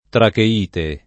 tracheite [ trake & te ]